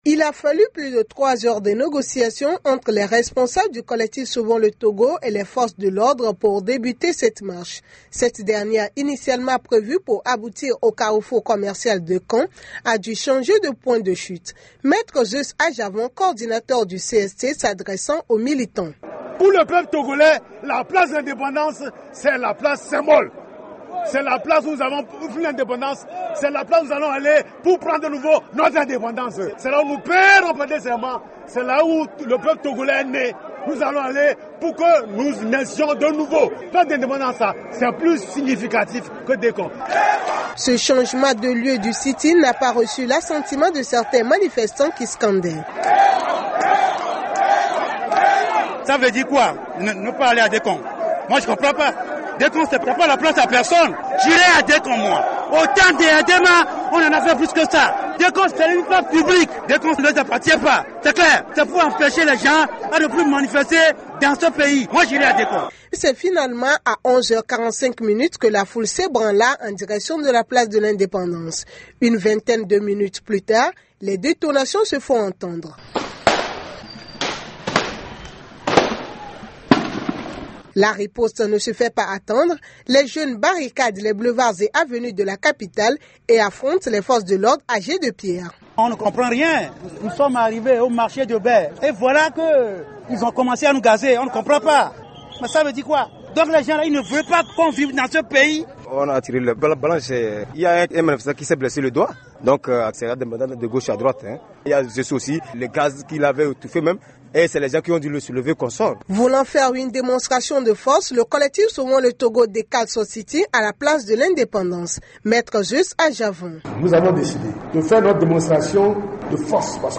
Un reportage
à Lomé